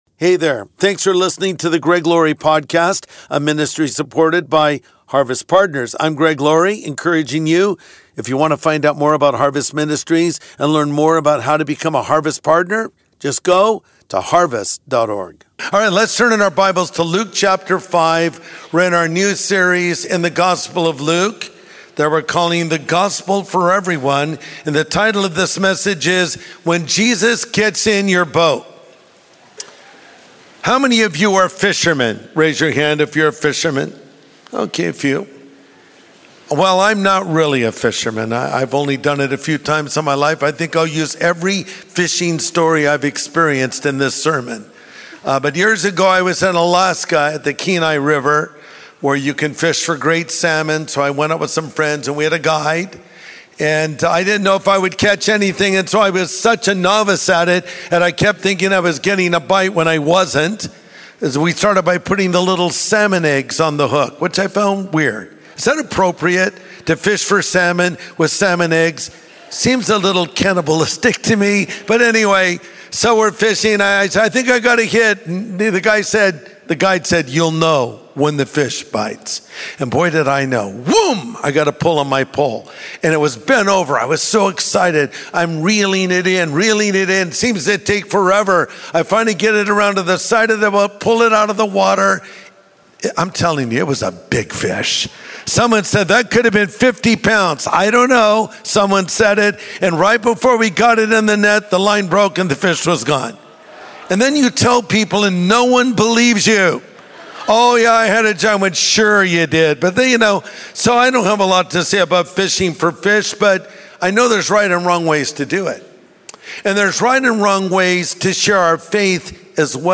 Evangelism is a God-given calling every Christian must answer in obedience. In this message, Pastor Greg Laurie shares principles of a good "fisherman."